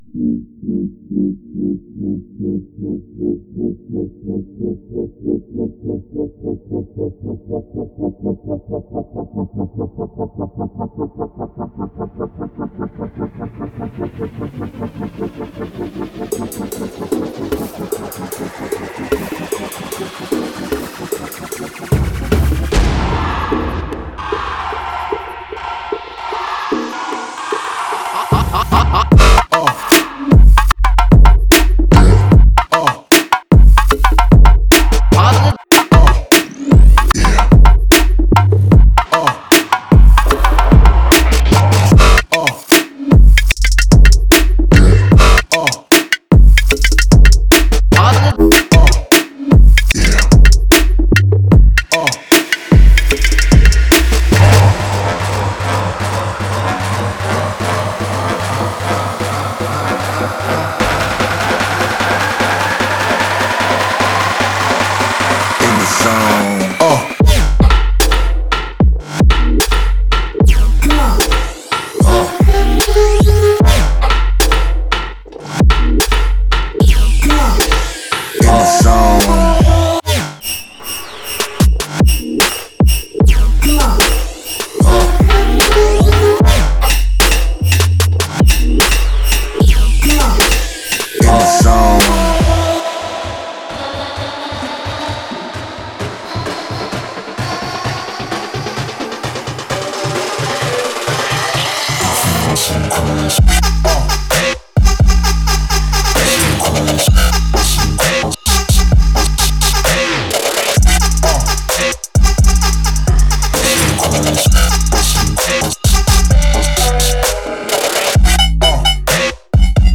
疯狂的合成音色和旋律循环，808以及失真的主音让您发疯。
通过玩弄民族旋律和部落打击乐循环，为您的曲目增添些真实的狂野感觉  。